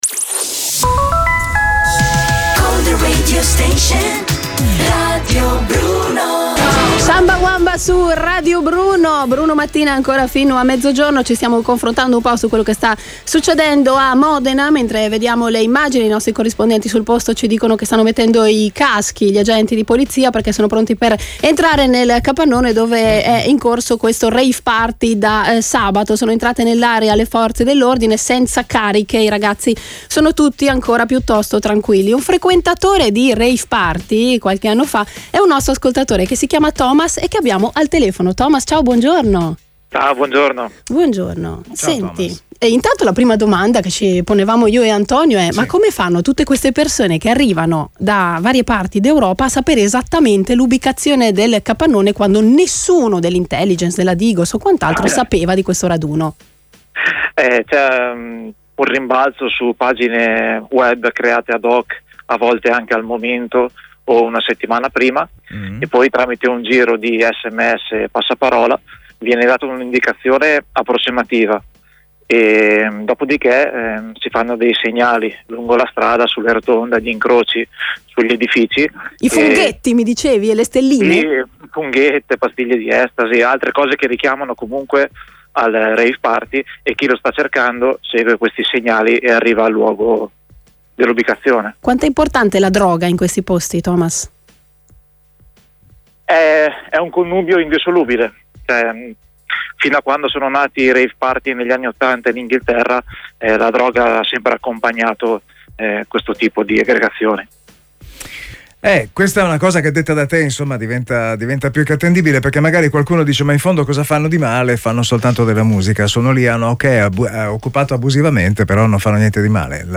intervista-rave-party.mp3